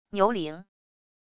牛铃wav音频